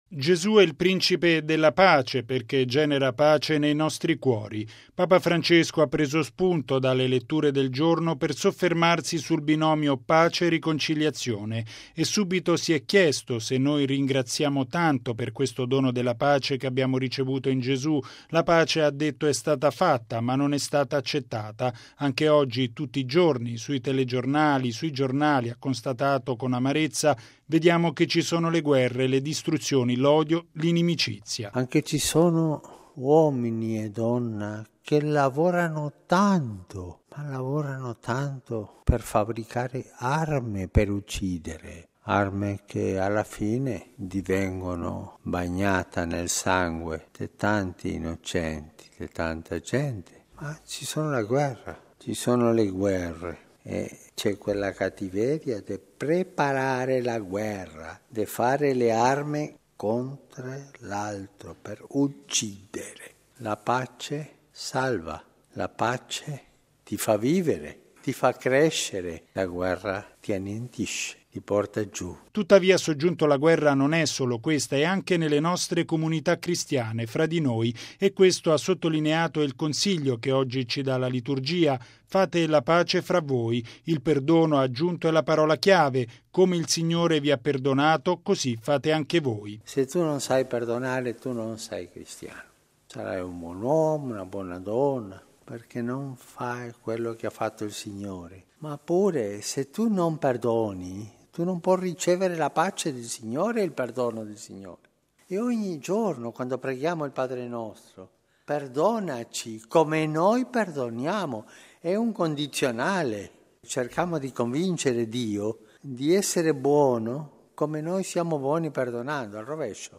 Papa Francesco ha sviluppato l’omelia nella Messa mattutina a Casa Santa Marta partendo da questo binomio. Il Pontefice ha condannato quanti producono armi per uccidere nelle guerre, ma ha anche messo in guardia dai conflitti all’interno delle comunità cristiane.